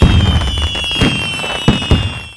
firework.wav